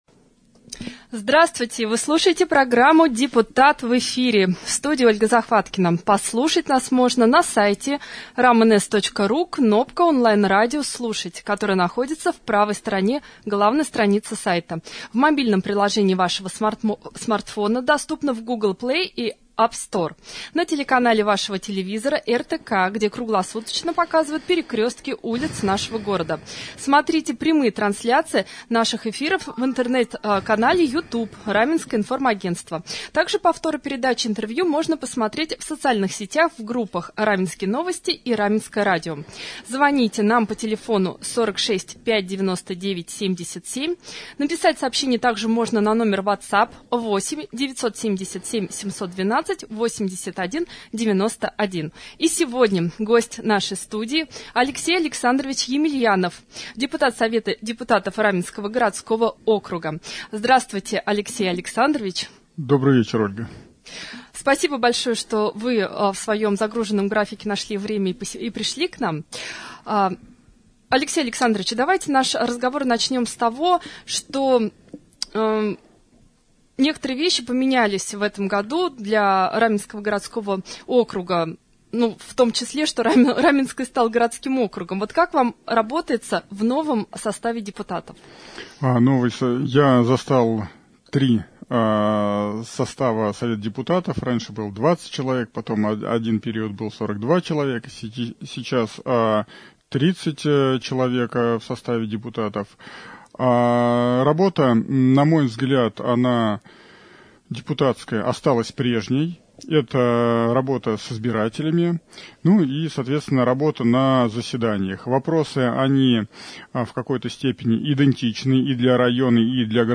19 марта гостем нашего эфира стал Алексей Емельянов – депутат Совета депутатов Раменского г.о. Мы обсудили ремонт дорог на территории МКУ ТУ «Кратово», планы по благоустройству, вывоз мусора и поездку на современный комплекс по переработке отходов в Егорьевске, в ходе передачи Алексей Емельянов ответил на вопросы жителей.